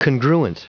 Prononciation du mot congruent en anglais (fichier audio)
Prononciation du mot : congruent